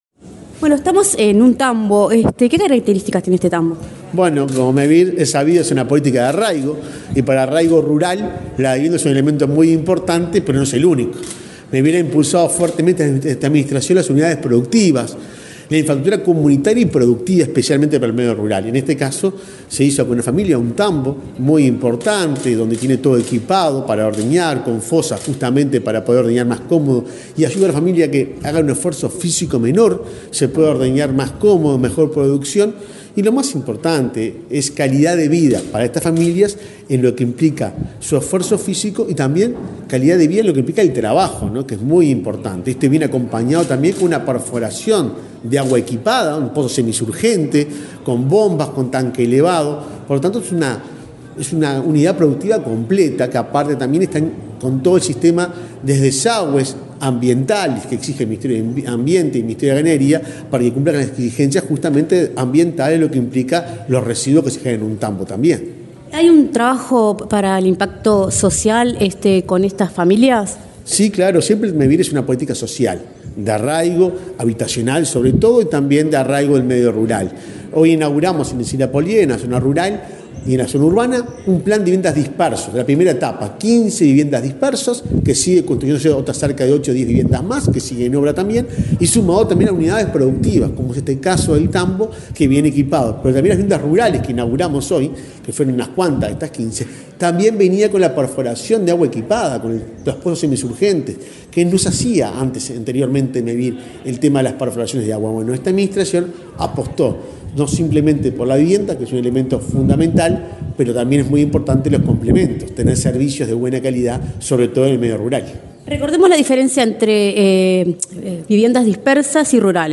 Entrevista al presidente de Mevir, Juan Pablo Delgado